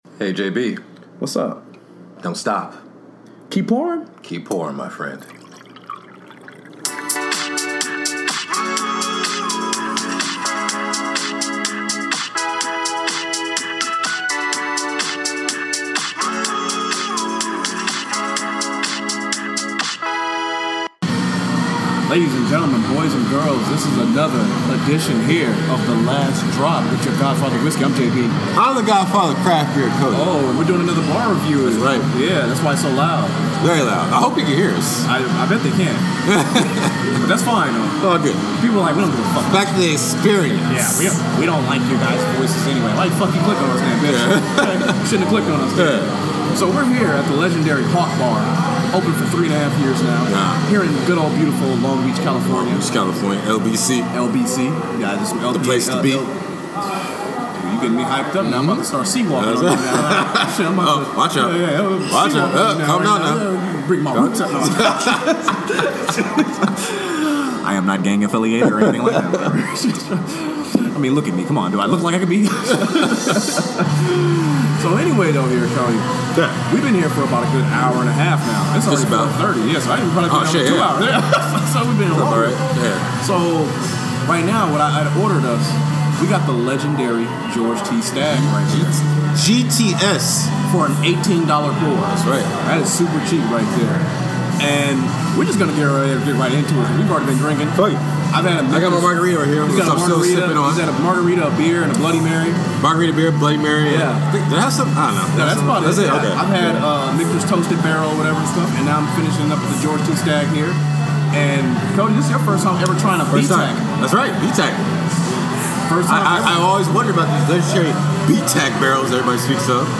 We visit a local bar in the heart of Long Beach, CA known simply as The Hawk.